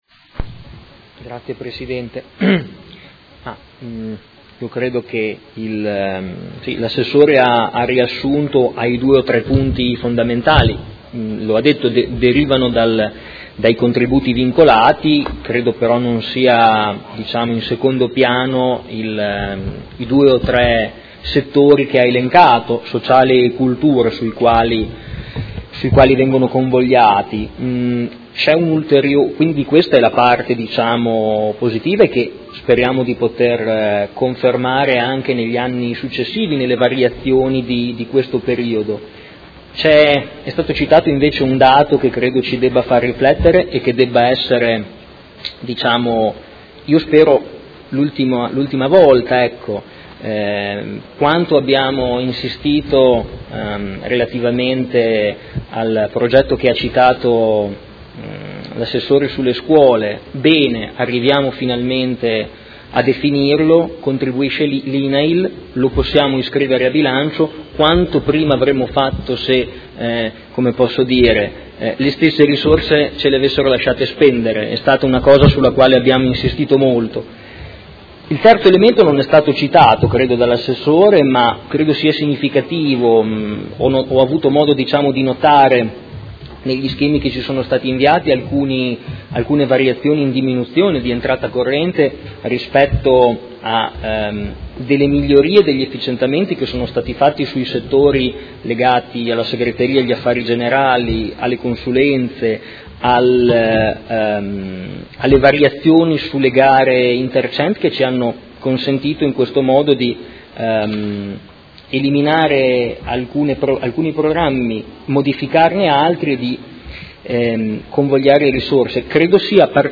Marco Forghieri — Sito Audio Consiglio Comunale